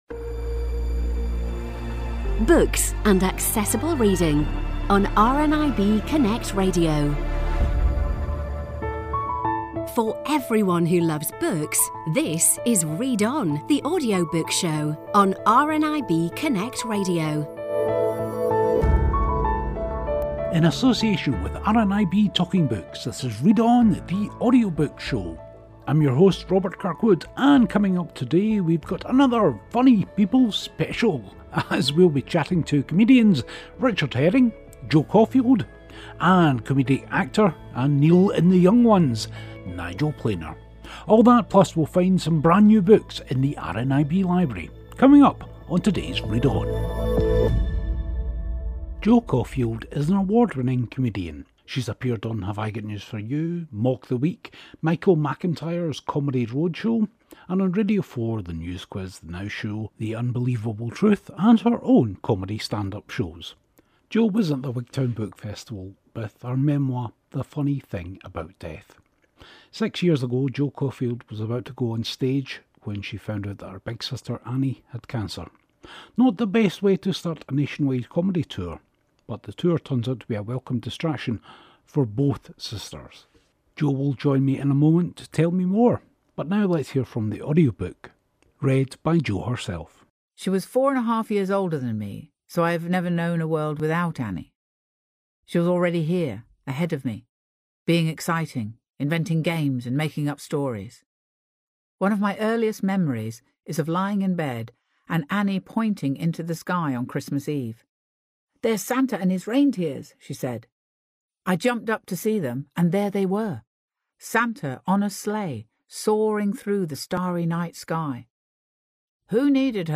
Another comedian compilation as we hear from stand-ups Jo Caulfield and Richard Herring and comedic actor, audiobook narrator and Neil from The Young Ones, Nigel Planer.